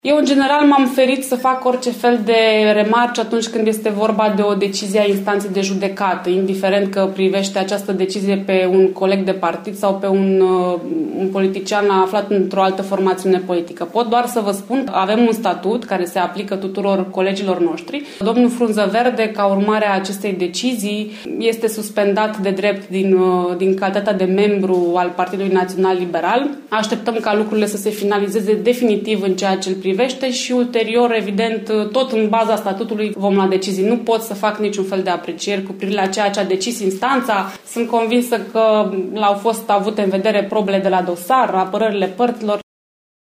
Nici copreședintele PLN Caraș-Severin, deputatul Valeria Schelean, nu comentează decizia Tribunalului Caraş-Severin: